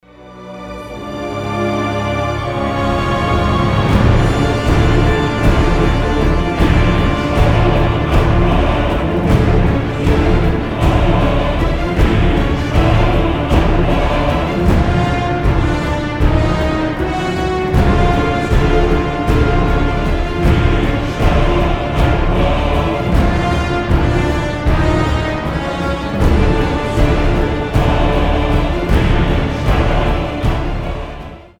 • Качество: 320, Stereo
пугающие
страшные
оркестр
эпичные